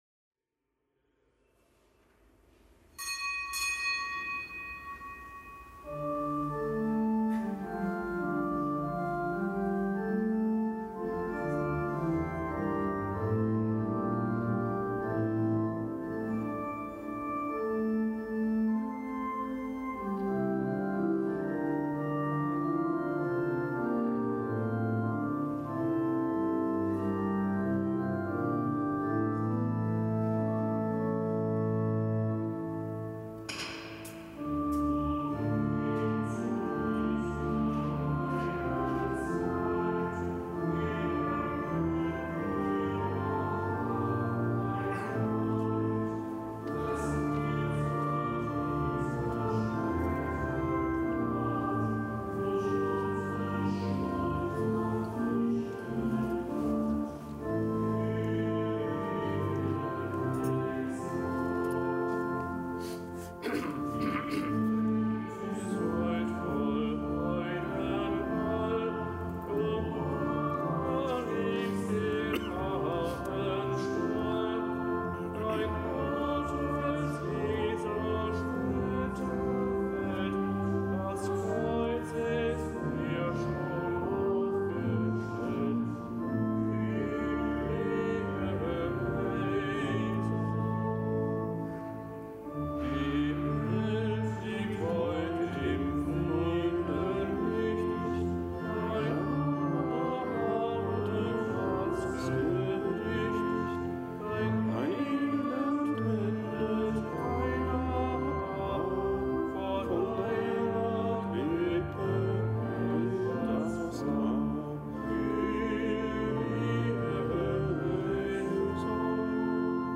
Kapitelsmesse aus dem Kölner Dom am Montag der Weihnachtszeit. Zelebrant: Weihbischof Rolf Steinhäuser.